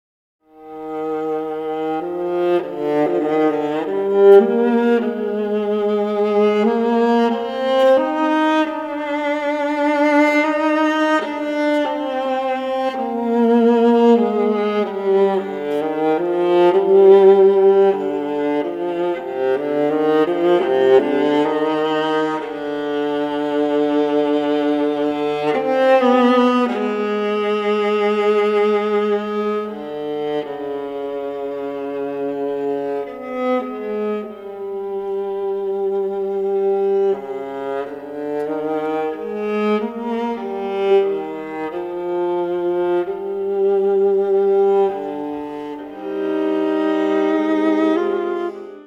LISTEN 22 Adagio Espressivo
Recorded at: Music Works Recordillg Studios,